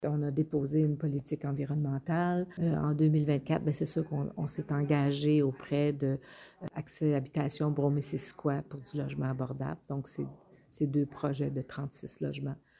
La mairesse de Cowansville, Sylvie Beauregard, revient sur certains projets qui ont vu le jour en 2024 :